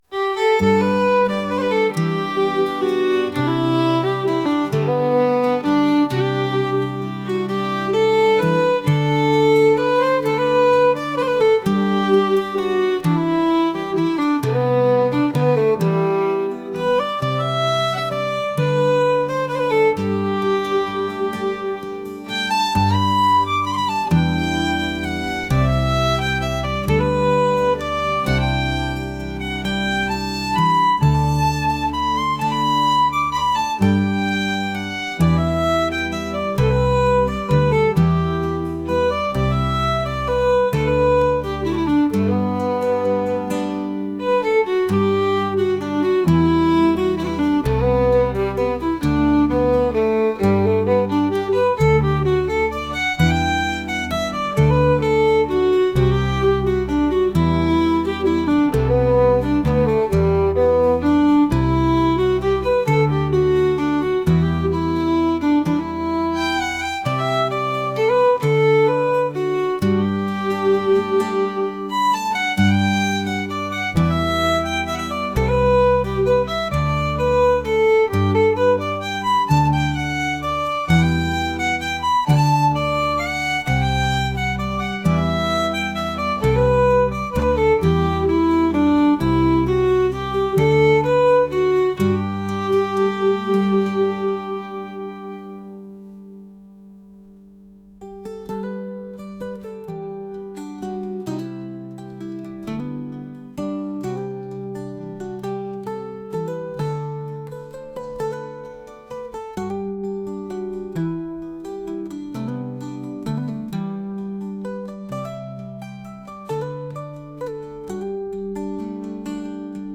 風車を前にした穏やかなケルト音楽です。